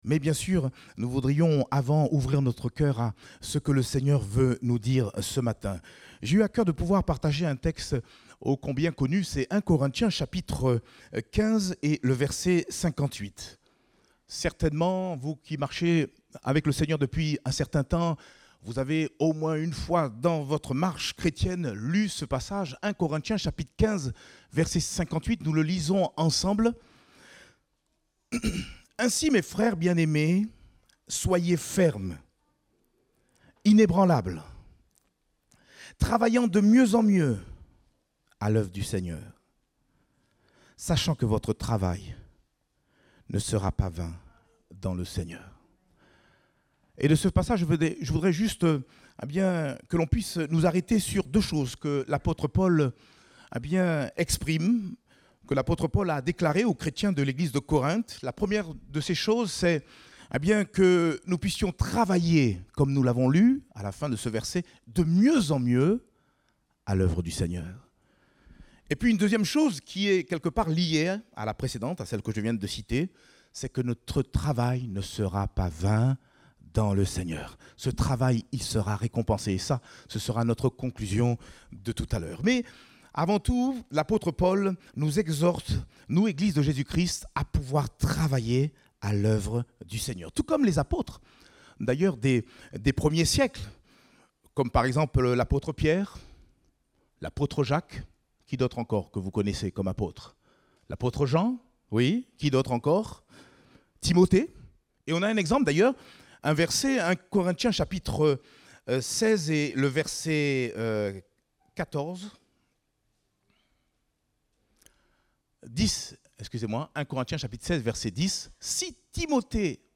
Date : 19 juin 2022 (Culte Dominical)